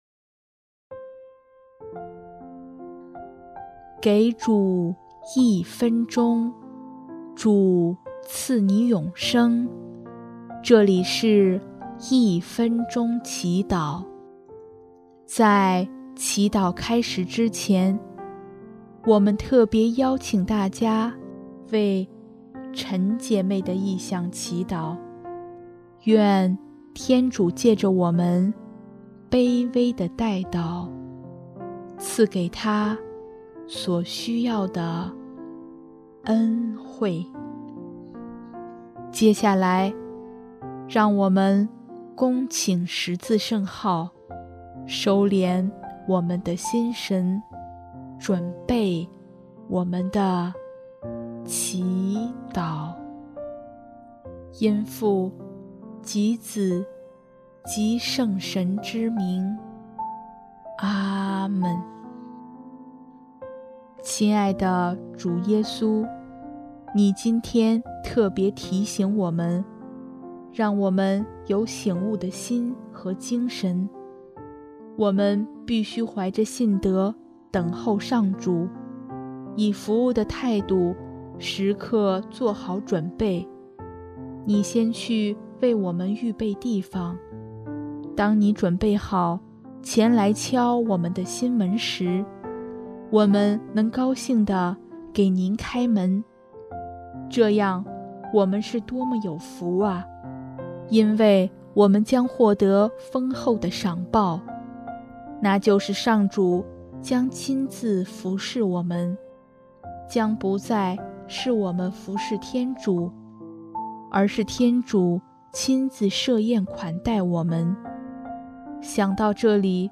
【一分钟祈祷】|10月22日 醒悟等待主人的到来